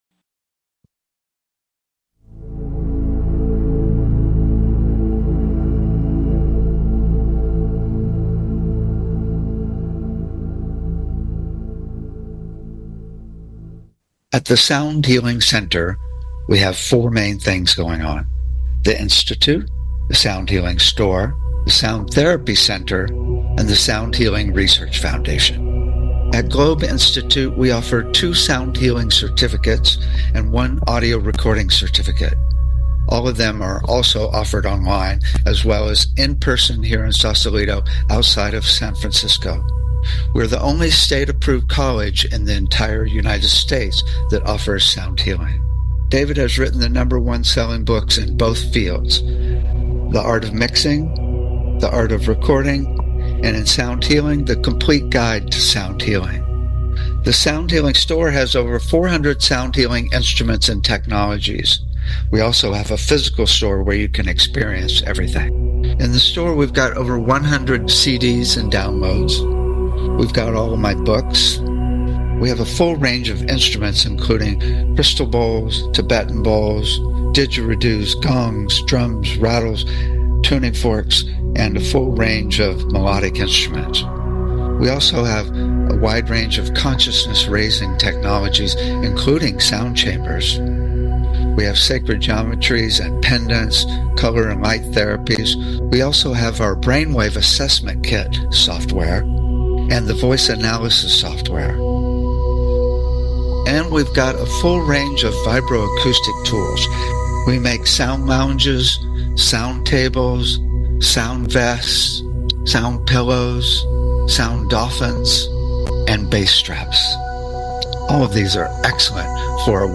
Talk Show Episode, Audio Podcast, Sound Healing and All About SERVICE, Selfishness vs Service, The Sound Loving Relationships book, World Sound Healing Day on , show guests , about All About SERVICE,Selfishness vs Service,The Sound Loving Relationships book,World Sound Healing Day, categorized as Education,Energy Healing,Sound Healing,Love & Relationships,Emotional Health and Freedom,Mental Health,Science,Self Help,Spiritual